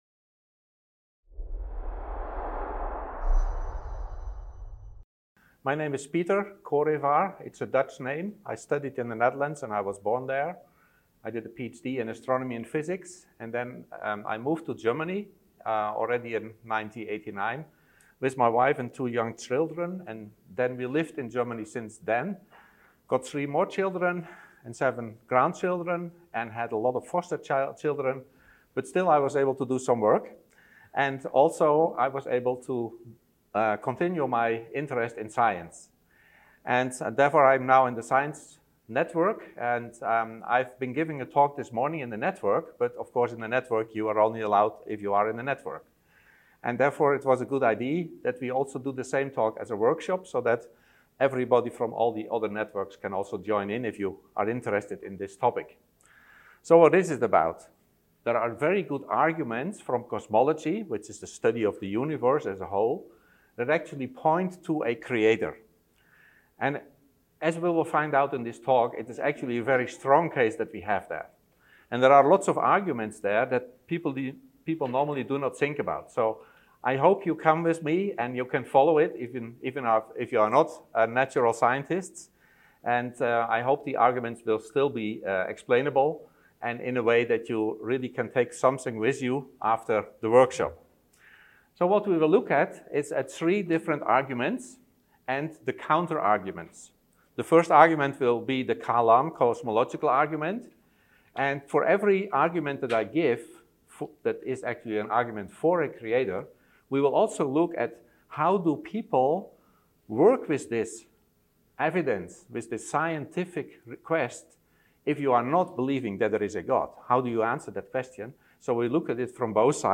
Event: ELF Workshop